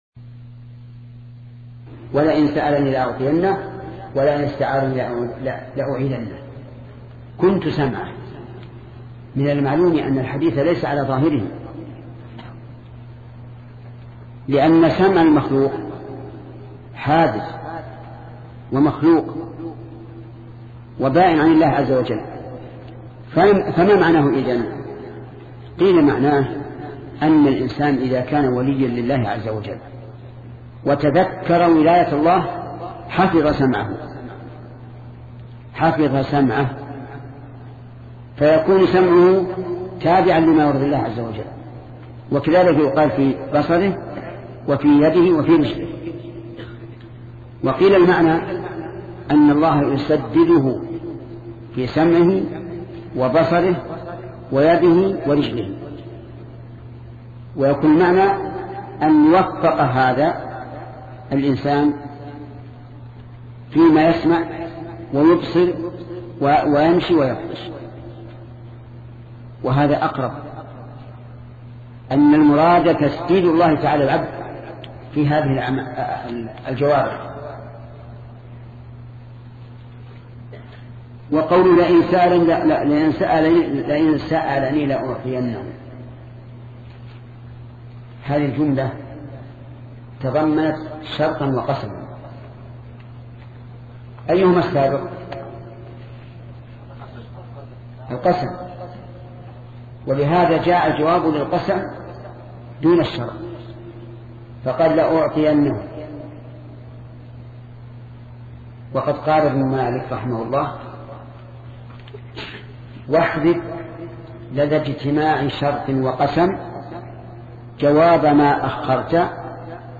سلسلة مجموعة محاضرات شرح الأربعين النووية لشيخ محمد بن صالح العثيمين رحمة الله تعالى